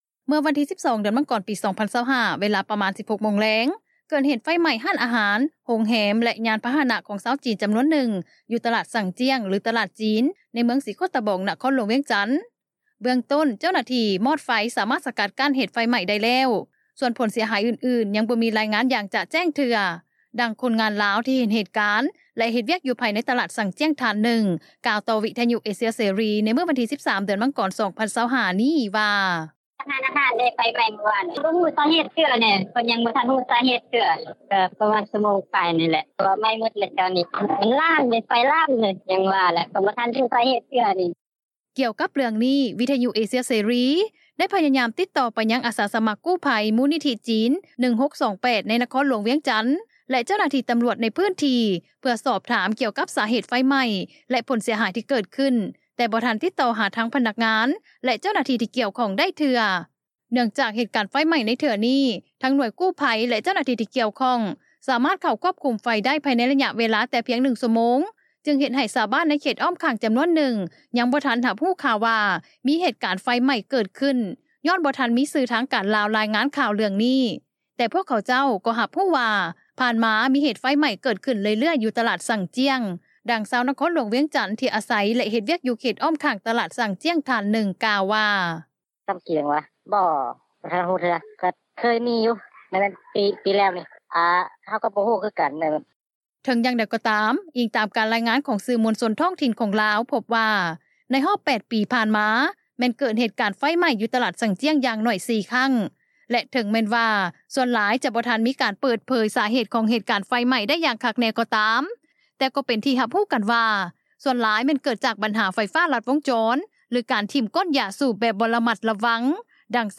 ດັ່ງຄົນງານລາວ ທີ່ເຫັນເຫດການ ແລະ ເຮັດວຽກ ຢູ່ພາຍໃນຕະຫຼາດຊັ່ງຈ່ຽງ ທ່ານໜຶ່ງ ກ່າວຕໍ່ວິທຍຸເອເຊັຽເສຣີ ໃນມື້ວັນທີ 13 ມັງກອນ 2025 ນີ້ວ່າ: